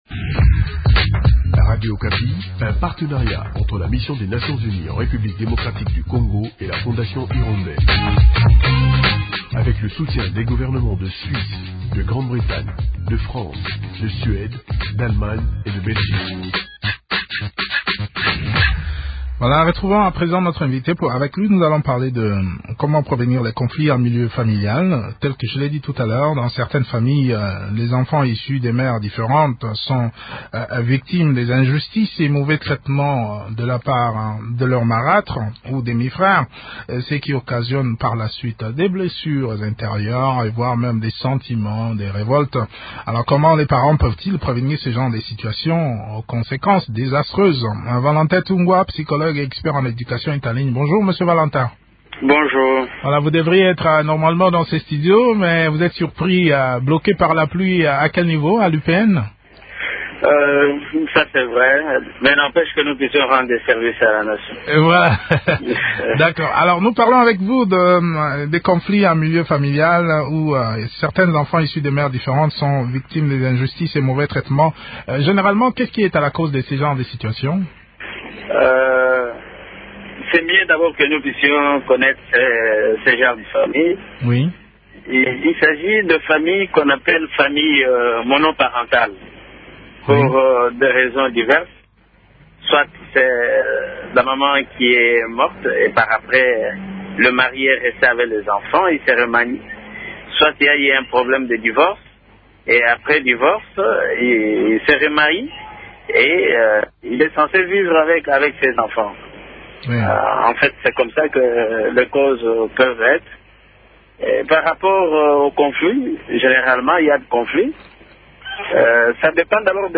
expert en sciences de l’éducation s’entretiennent sur le sujet.